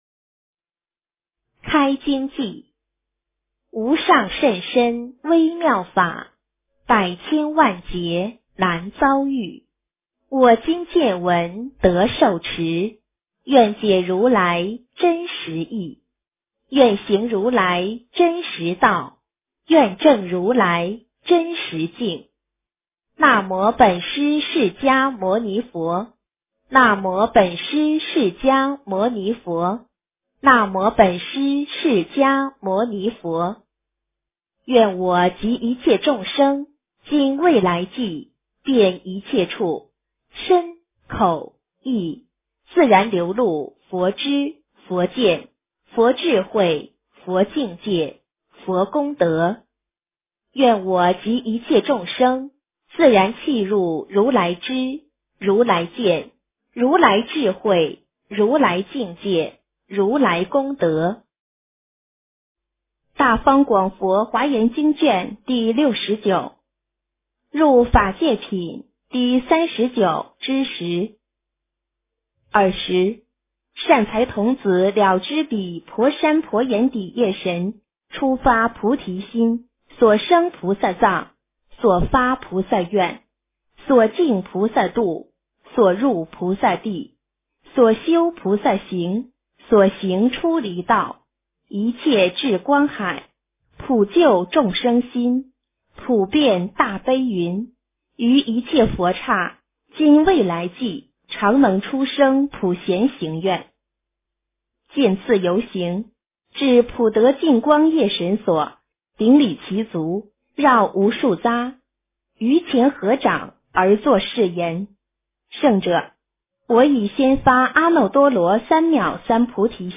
诵经